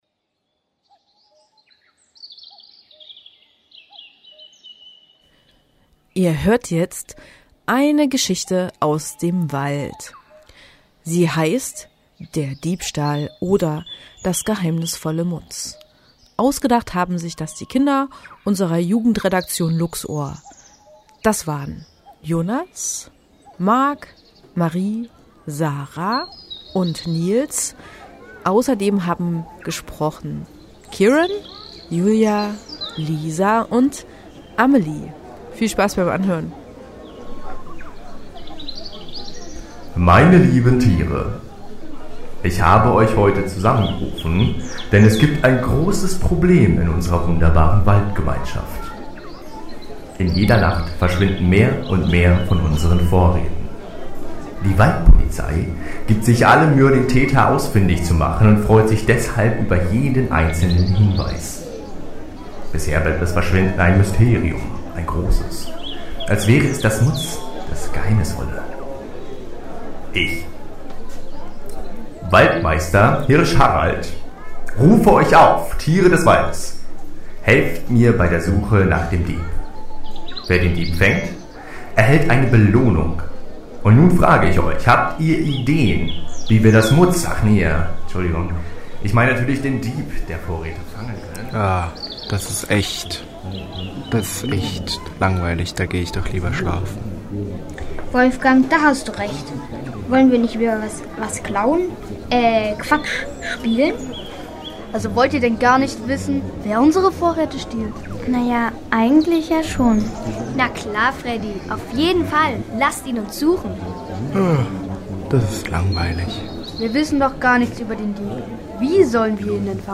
Wir haben mit beiden Bands gesprochen, was diesen Abend und was Jena für sie besonders macht.